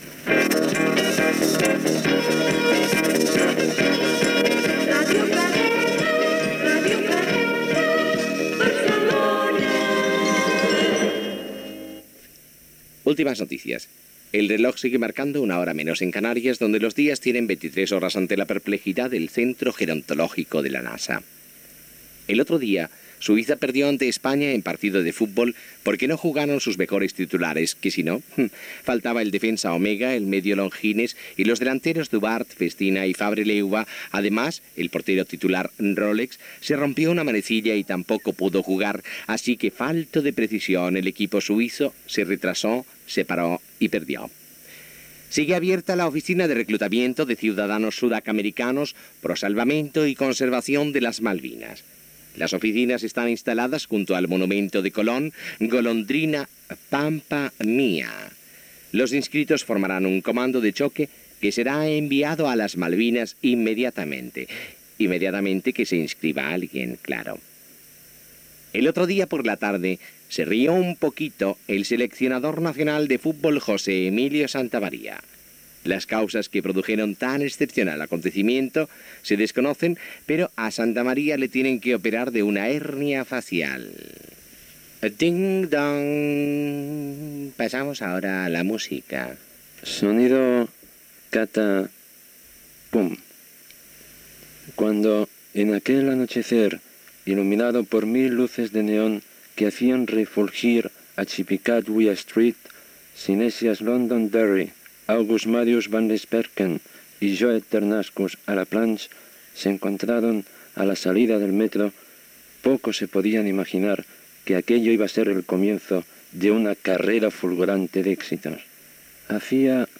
Indicatiu de l'emissora, noticiari: l'equip suís de futbol, reclutament per anar a les Malvines, el seleccionador espanyol de futbol.
Entreteniment